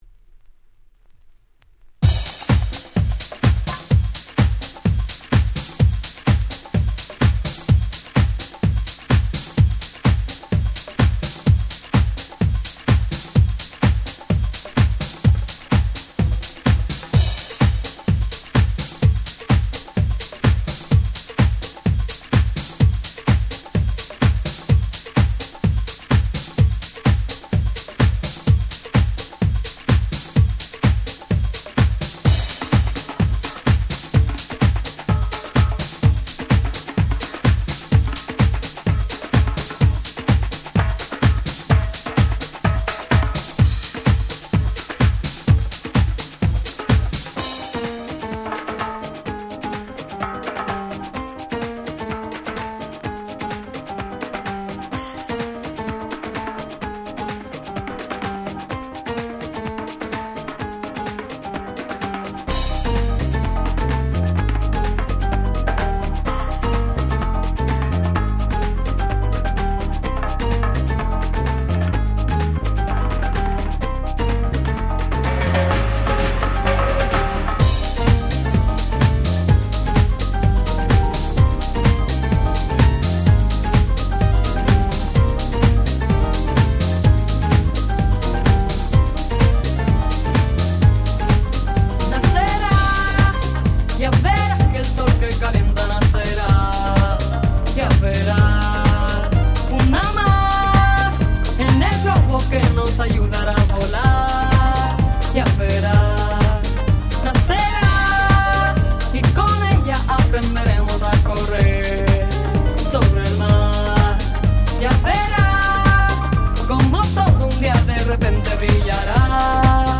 00' Super Nice Latin House !!
大人気定番ラテンハウス！！